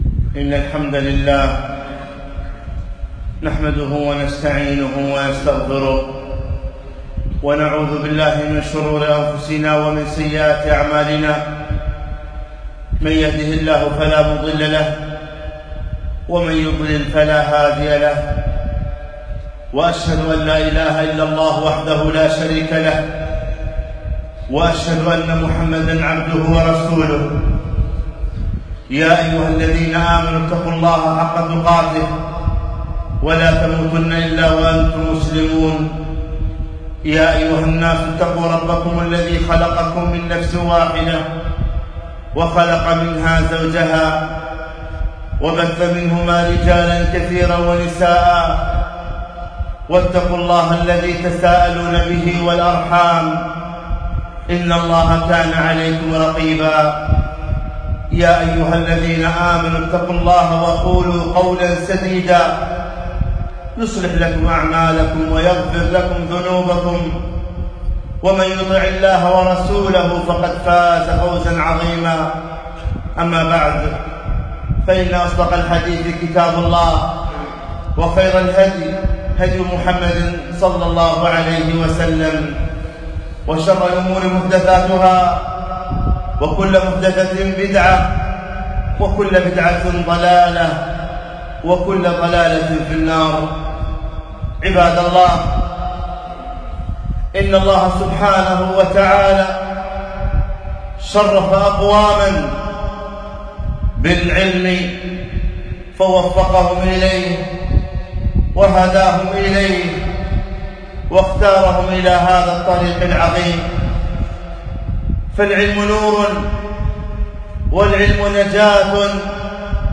خطبة - وصايا مهمة للمعلمين والطلاب